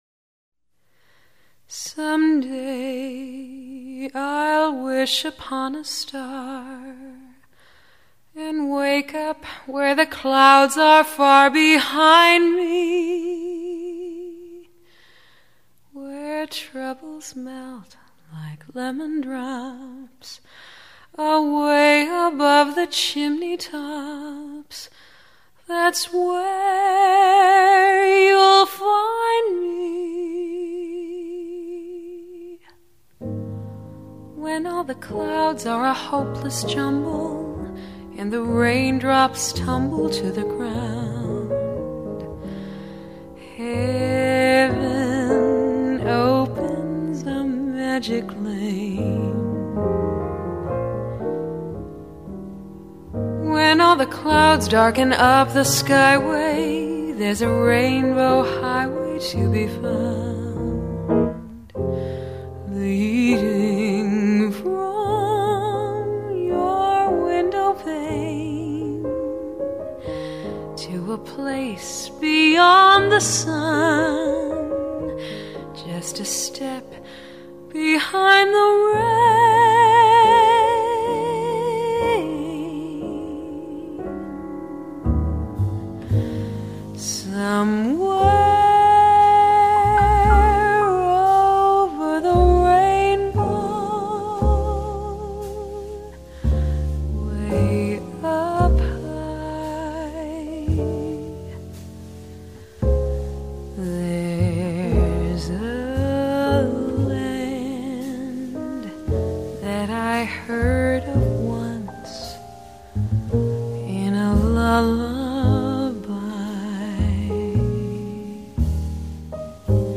音樂類型：爵士樂
唱歌的人輕輕柔柔唱出在子夜裡的獨白，鋼琴前奏更舒展了女人晨起前的內心戲…